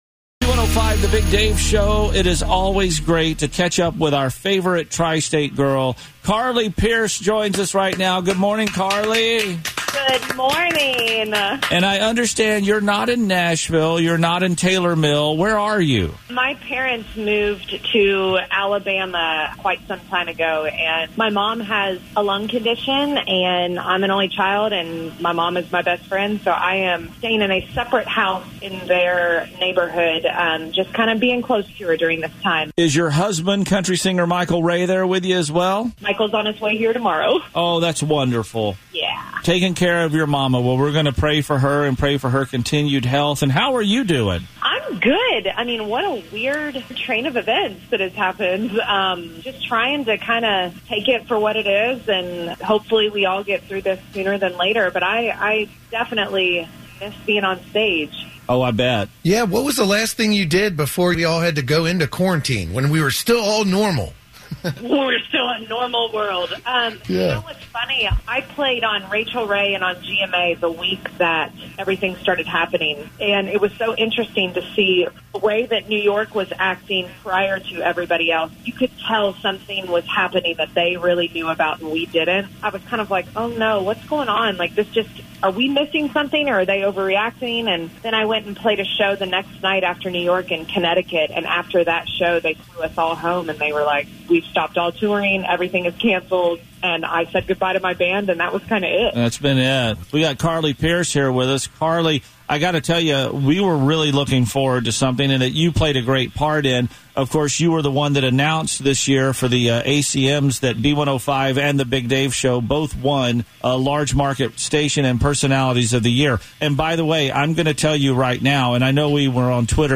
Keeper-20-Carly-Pearce-Inteview.mp3